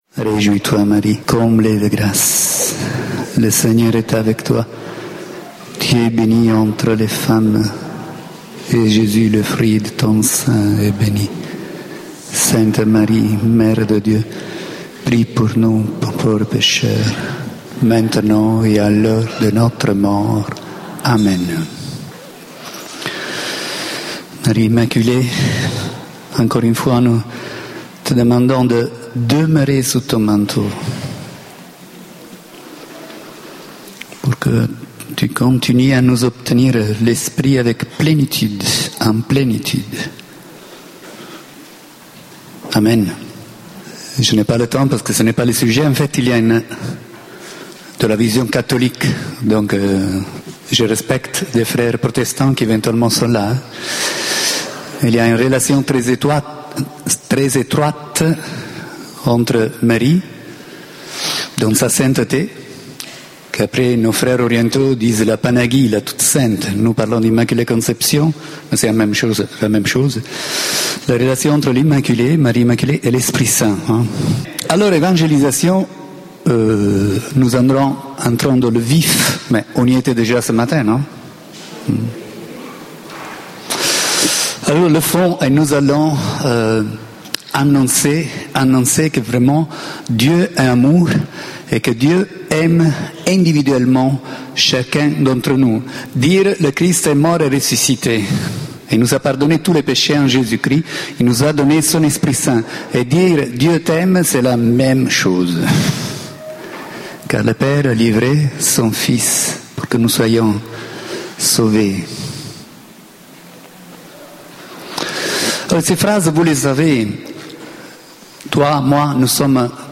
Regardons J�sus et faisons comme lui... Enregistr� en 2012 (Rassemblement annuel organis� par les groupes de pri�re de Laval et Rennes)Intervenant(s